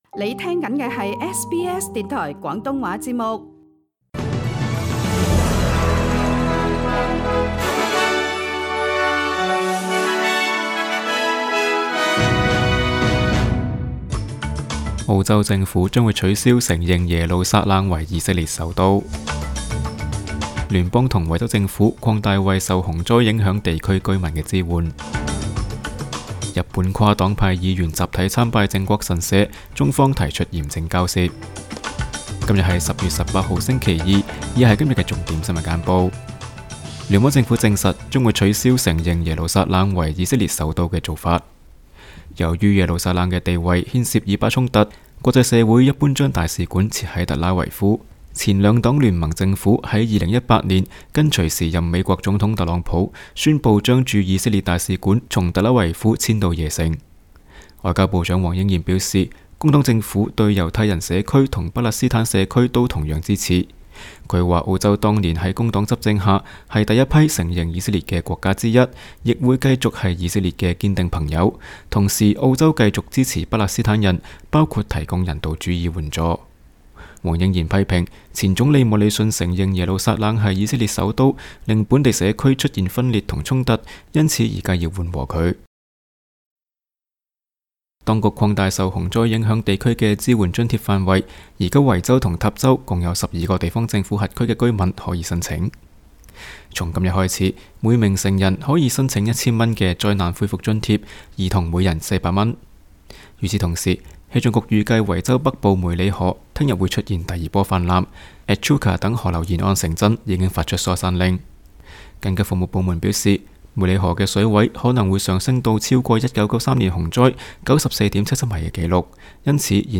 SBS 新聞簡報（10月18日）